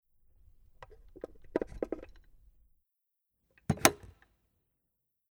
Portables Radiogerät TS502
Aufklappen zuklappen
0852_Aufklappen_zuklappen.mp3